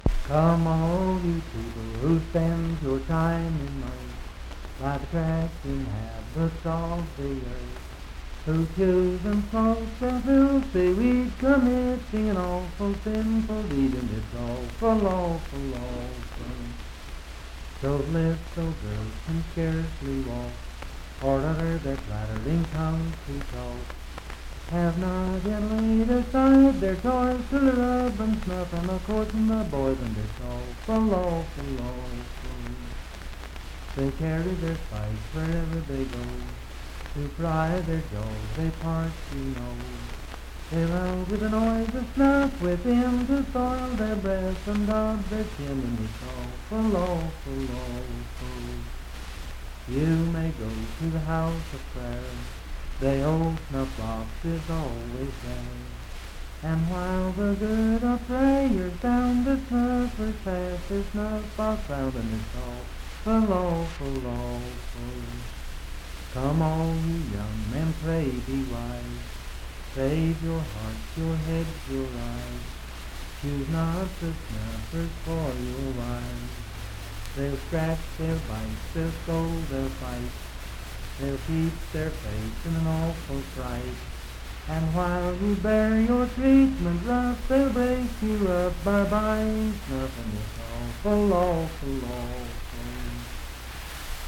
Unaccompanied vocal music
Verse-refrain 4d(5-7w/R).
Voice (sung)
Pocahontas County (W. Va.), Marlinton (W. Va.)